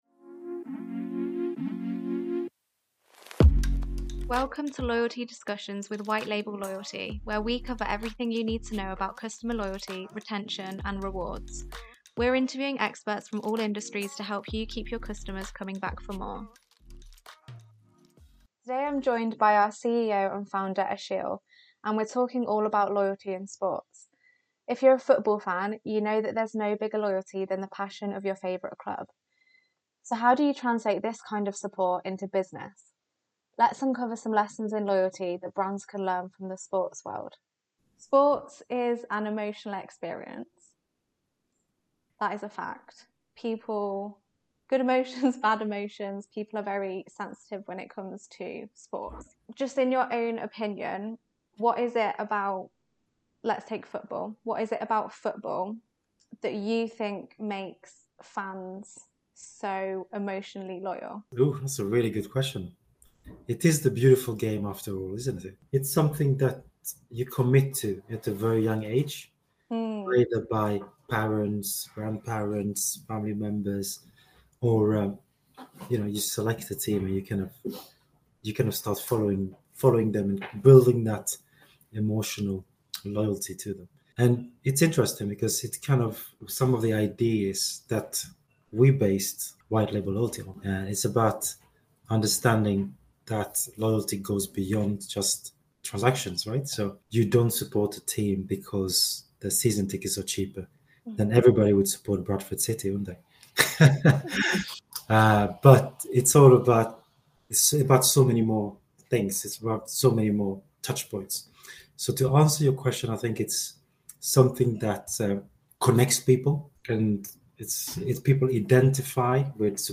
Loyalty in Sports: An interview with our CEO